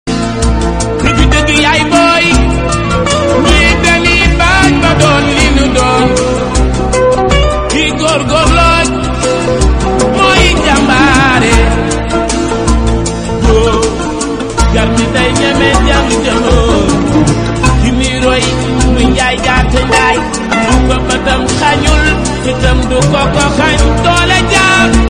groupe électrogène 850kva moteur cummins sound effects free download